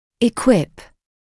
[ɪ’kwɪp][и’куип]оснащать, снабжать; оборудовать; давать (знания; образование)